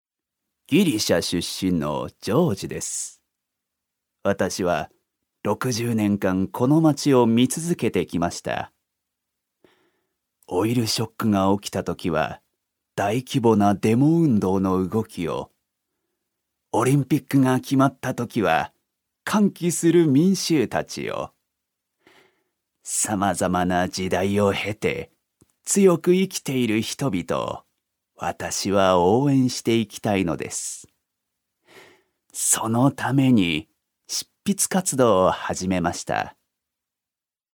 所属：男性タレント
セリフ５